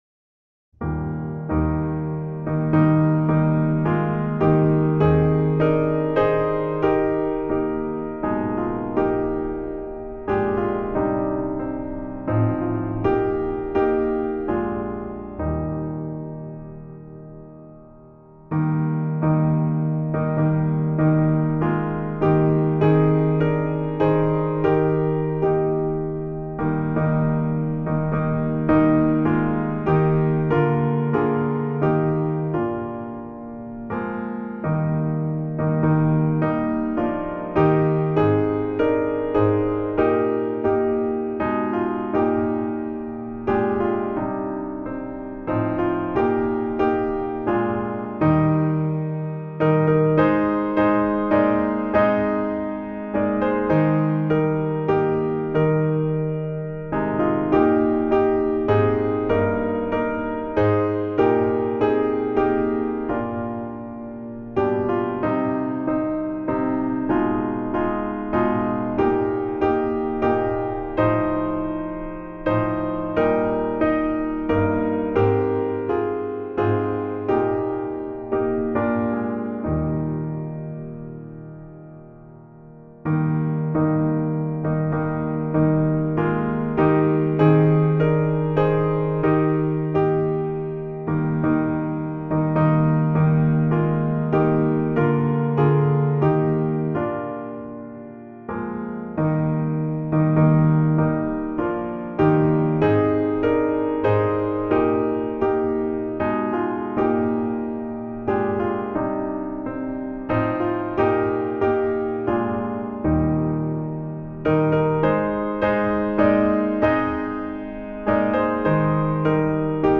Voicing/Instrumentation: SATB , Choir Unison